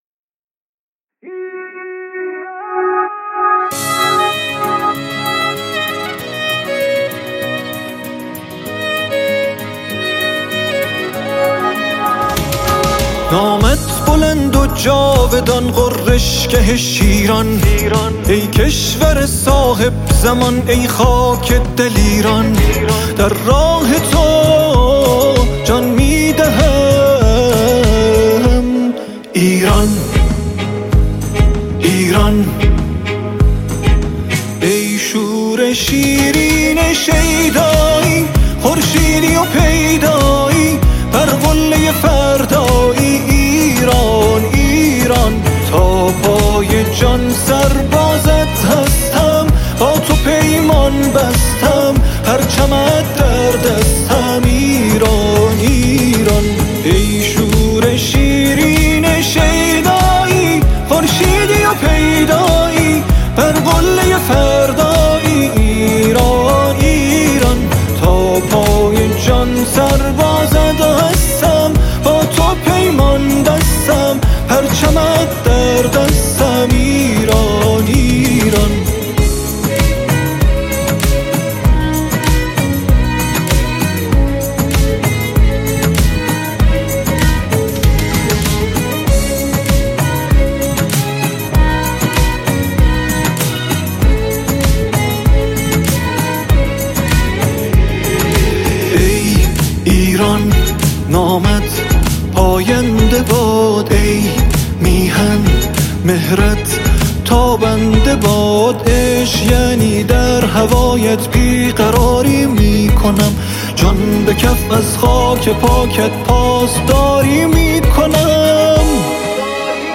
ترکیب فضای «شاد و عاشقانه» با «حس حماسی»
ژانر: آهنگ ، سرود انقلابی